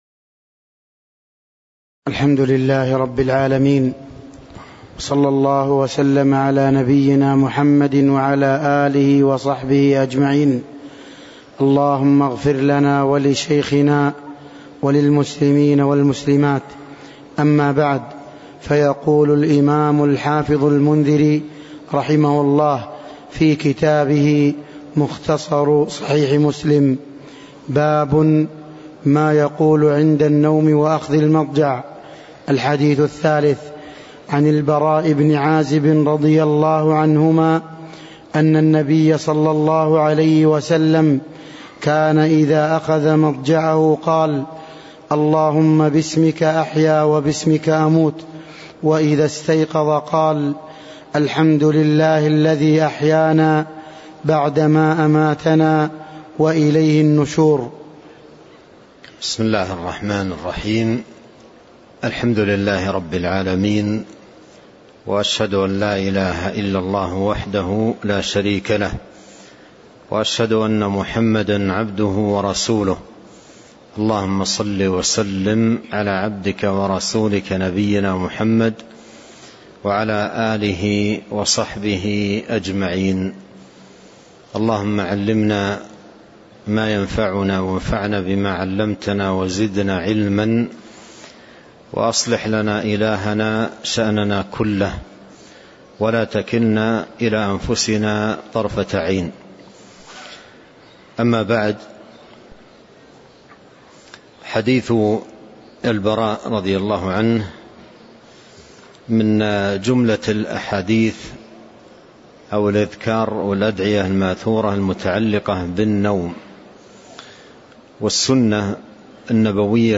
تاريخ النشر ٥ ذو الحجة ١٤٤٣ هـ المكان: المسجد النبوي الشيخ